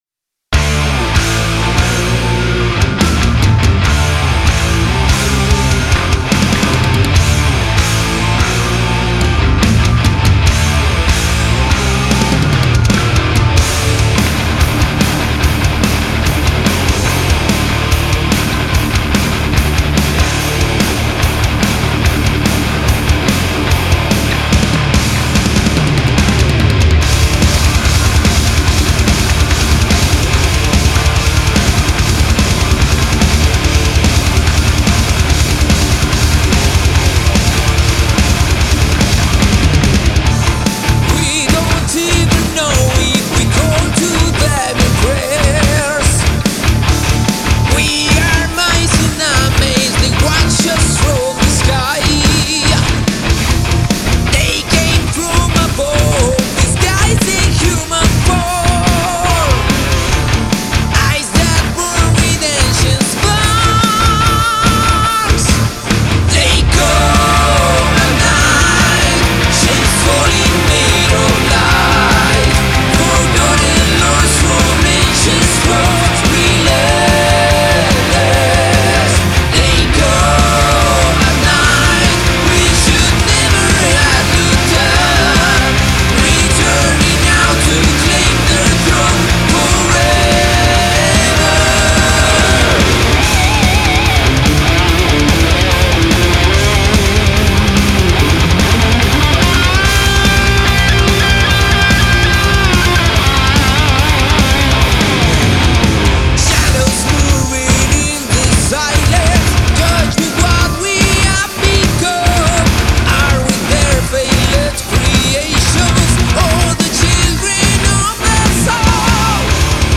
Powermetal
Intensidad, misterio y épica se funden en este nuevo single.
Guitarra y voz principal
Guitarra Líder
Bajo